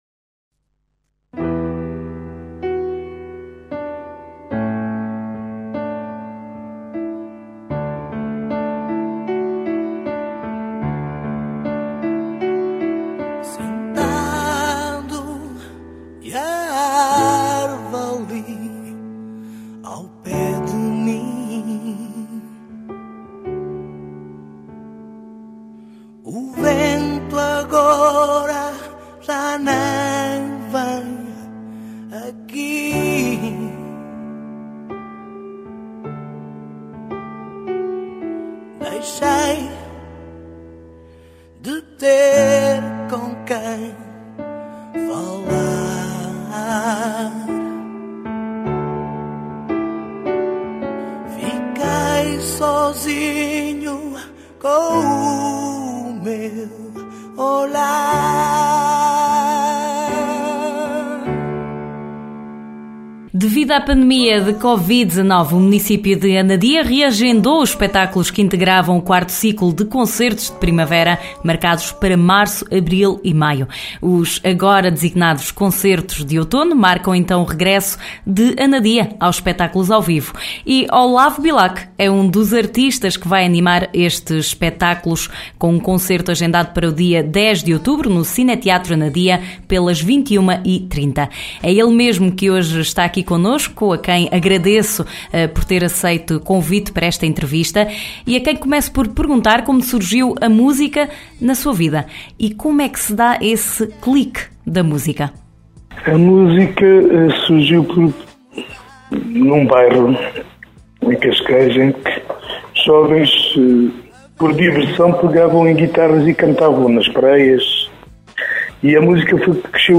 É um ‘Concertos de Outono’ promovidos pelo Município de Anadia e acontece hoje (10), às 21H30, no Cineteatro de Anadia. A Regional do Centro falou com o artista português que aproveita para deixar um convite.